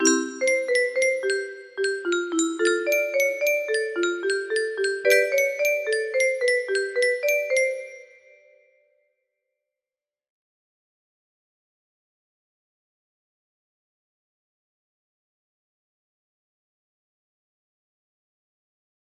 Test music box melody
Full range 60